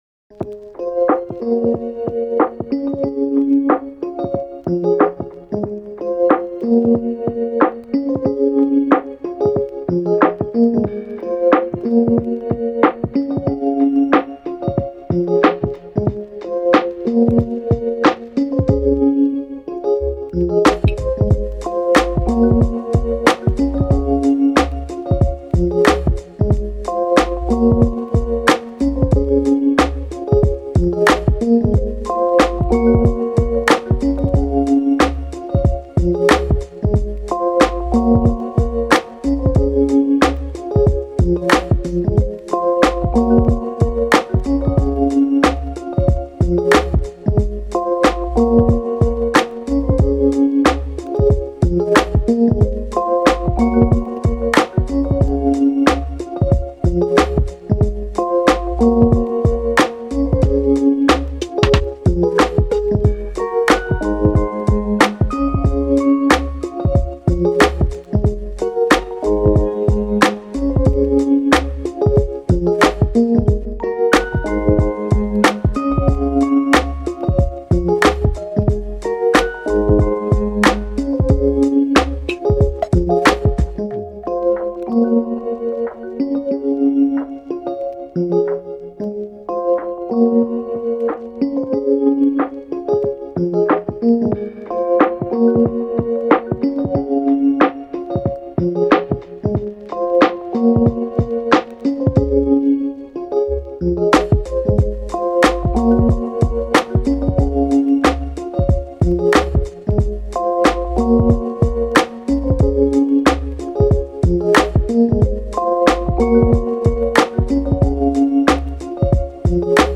カフェミュージック チル・穏やか